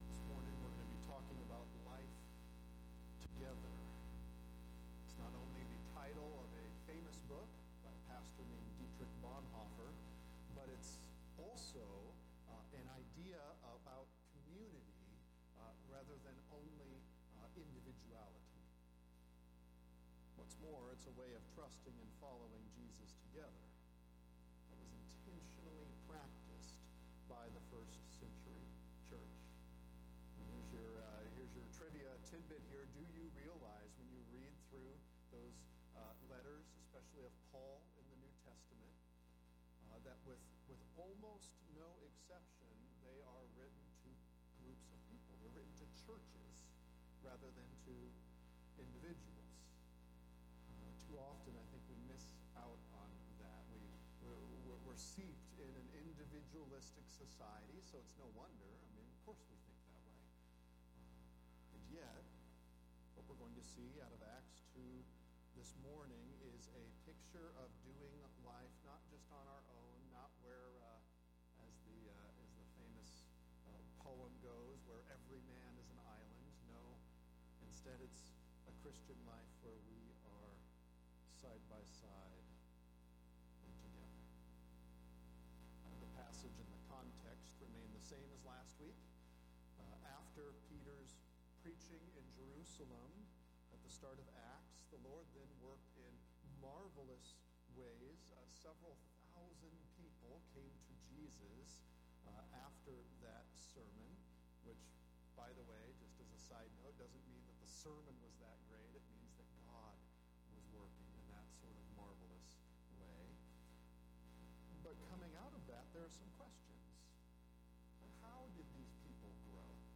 – Sermons